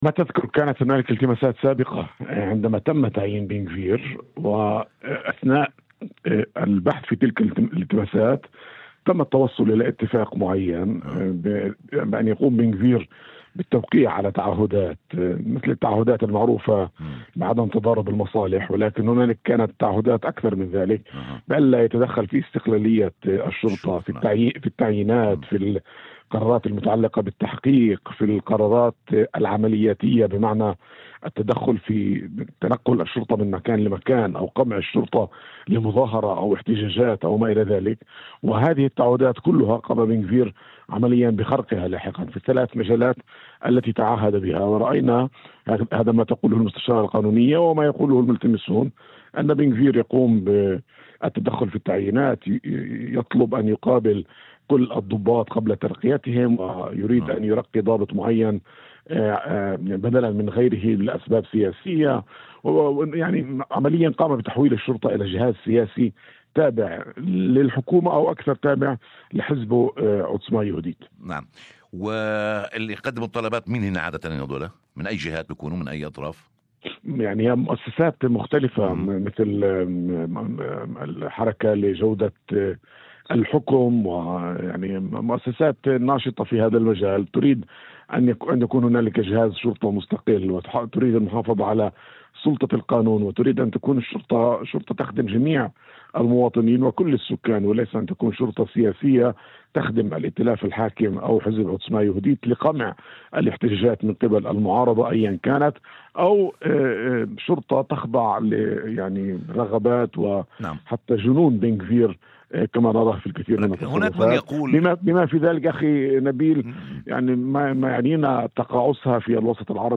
في مداخلة هاتفية ضمن برنامج "أول خبر" على إذاعة الشمس